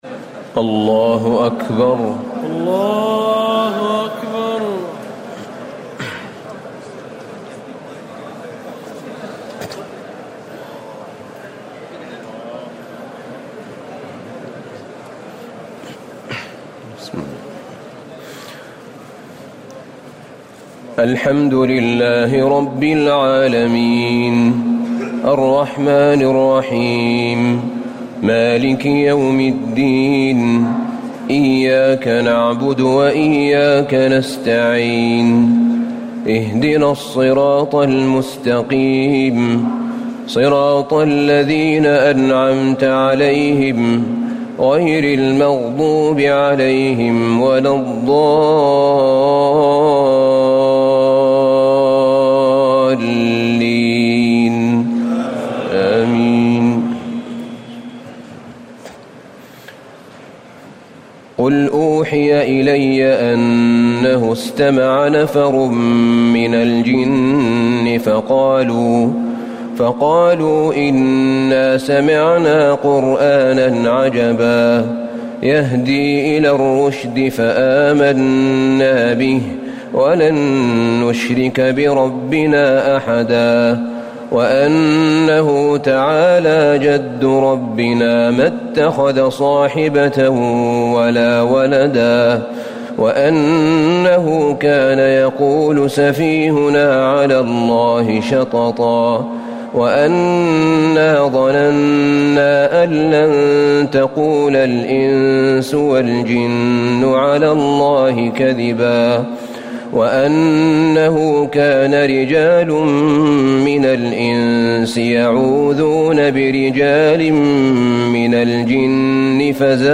تراويح ليلة 28 رمضان 1439هـ من سورة الجن الى المرسلات Taraweeh 28 st night Ramadan 1439H from Surah Al-Jinn to Al-Mursalaat > تراويح الحرم النبوي عام 1439 🕌 > التراويح - تلاوات الحرمين